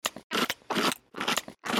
monster_chewing.mp3